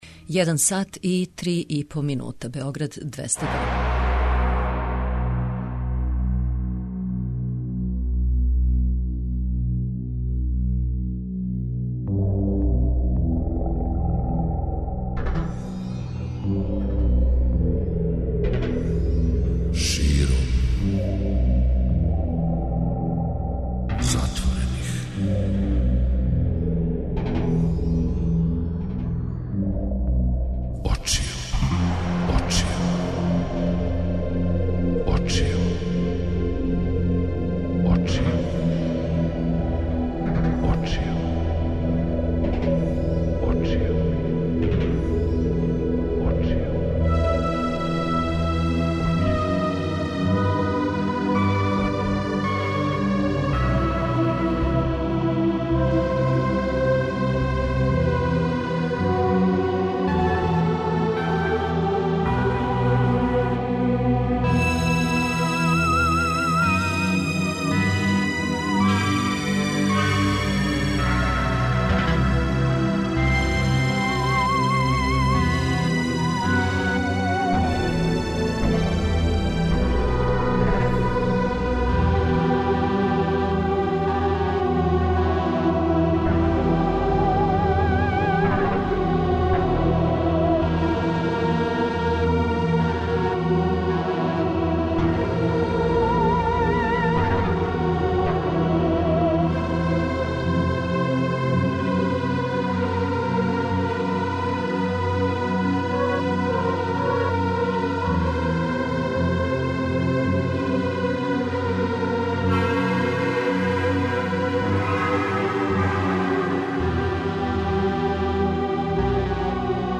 Поново, на захтев многобројних слушалаца, и ове ноћи уживамо у баладама, како страним, тако и домаћим.